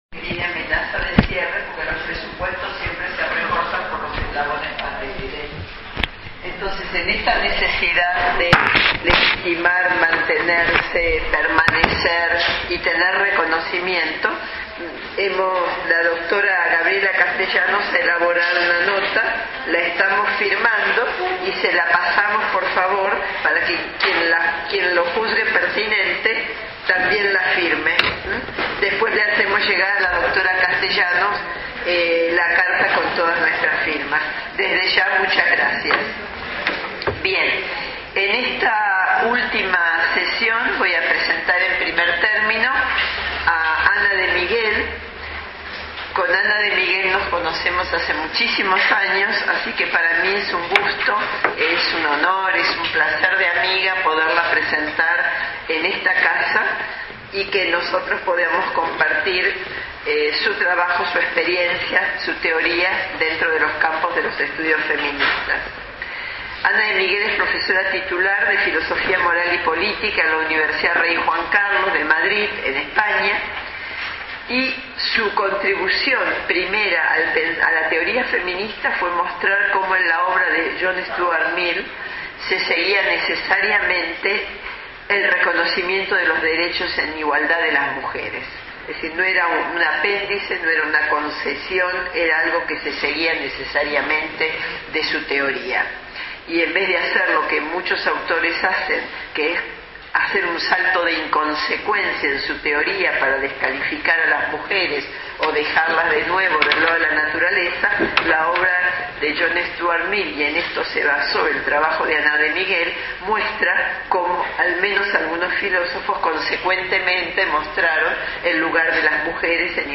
Panel de cierre II Jornadas 2011